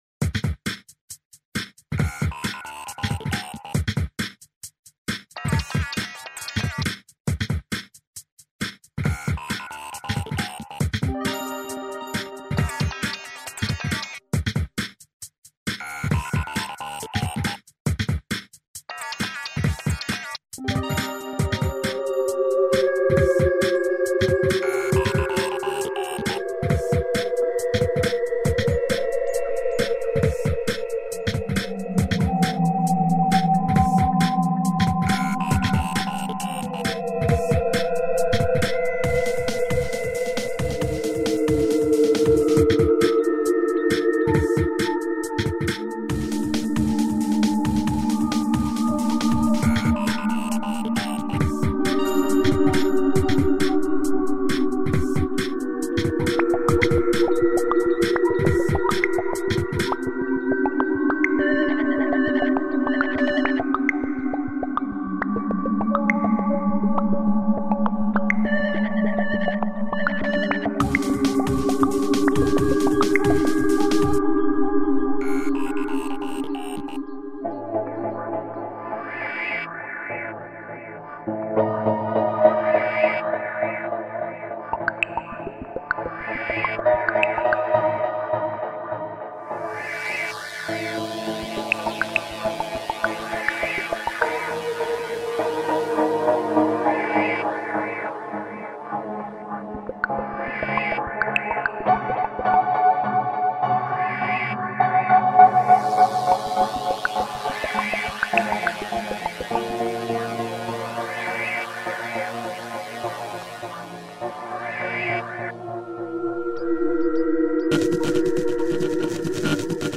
FILM SCORE MUSIC ; WEIRD MUSIC